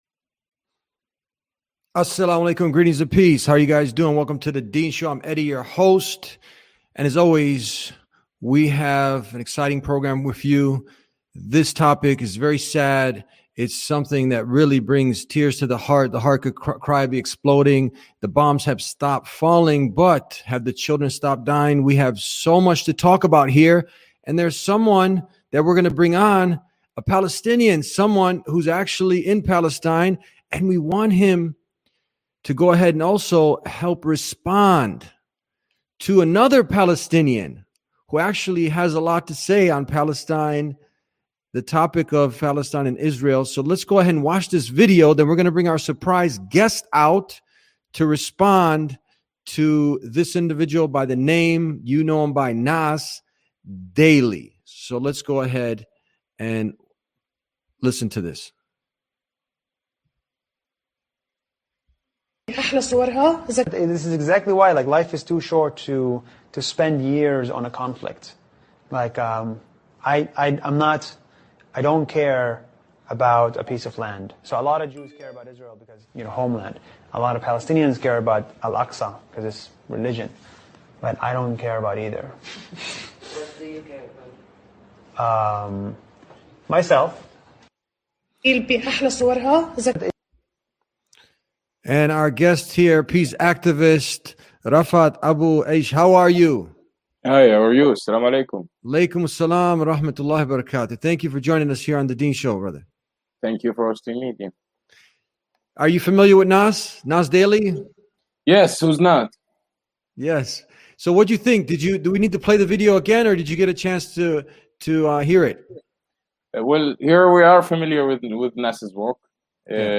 In this powerful episode of The Deen Show, a Palestinian peace activist living under occupation dismantles the dangerous narrative pushed by Nas Daily — a narrative that erases decades of suffering, whitewashes apartheid conditions, and tells Palestinians to simply “move on.”